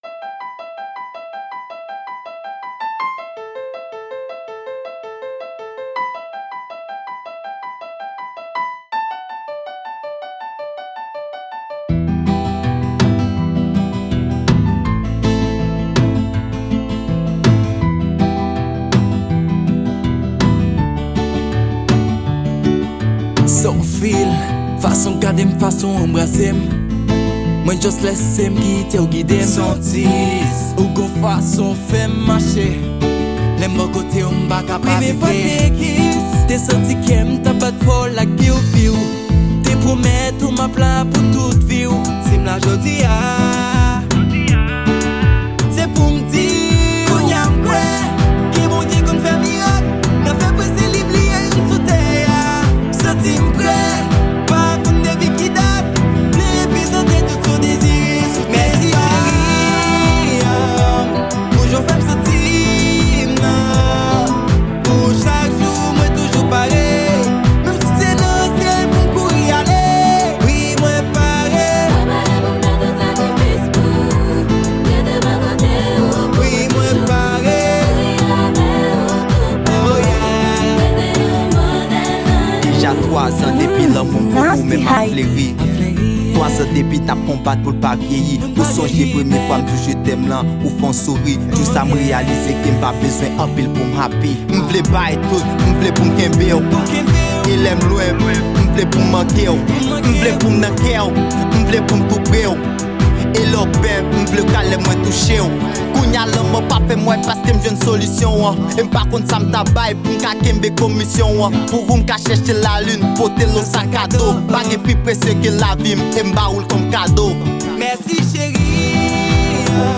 Genre: Kizoumba.